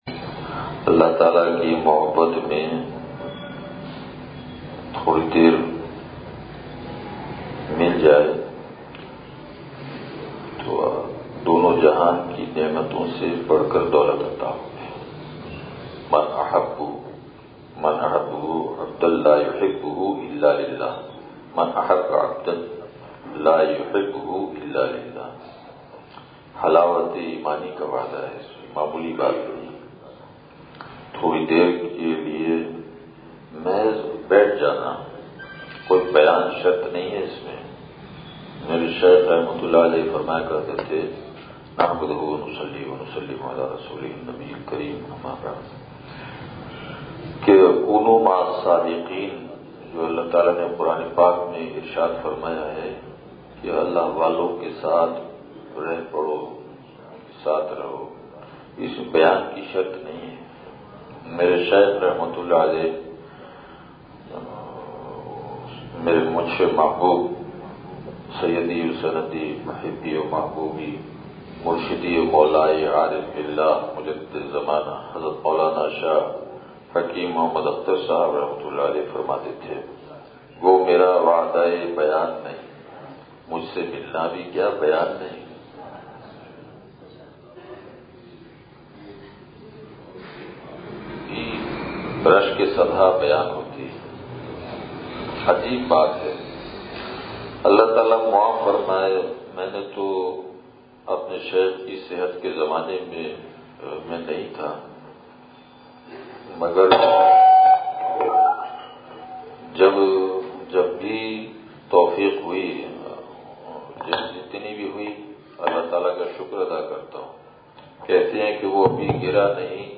سبز آرائیں مسجد پھلیلی حیدرآباد (بعد نمازِ عشاء)